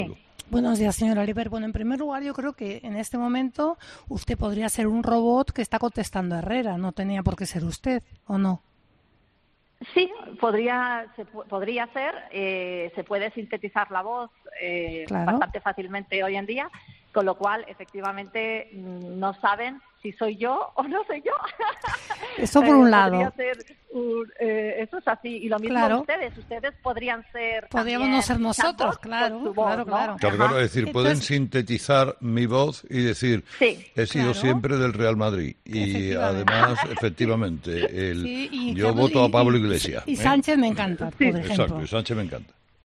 Escucha a Carlos Herrera pronunciar las tres frases que nunca pensaste que diría